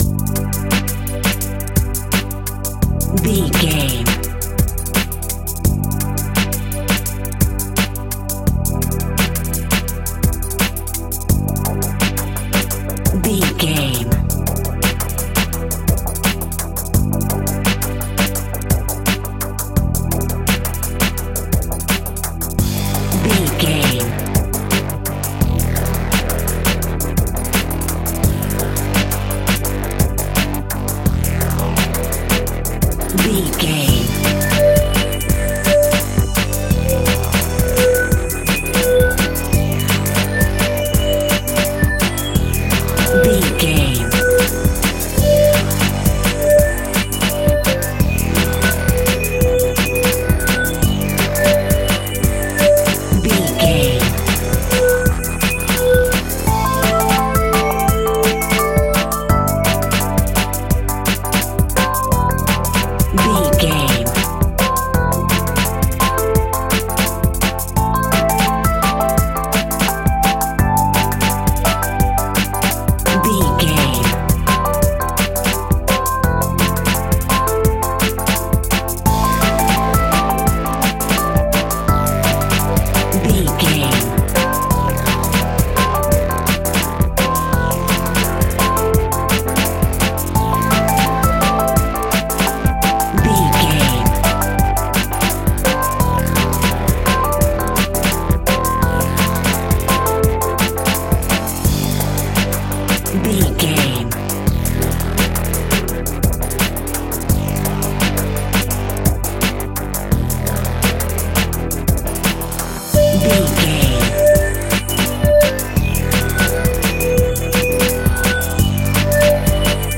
Aeolian/Minor
Fast
futuristic
hypnotic
industrial
dreamy
frantic
aggressive
powerful
synthesiser
drums
electronic
sub bass
synth leads